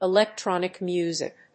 electrónic músic